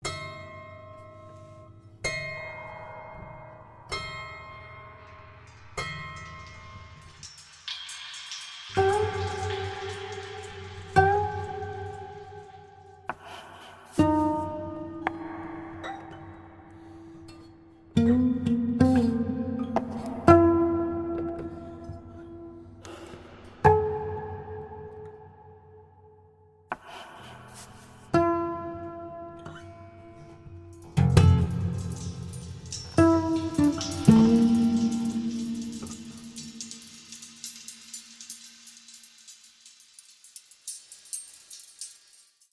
Electric guitar, Samples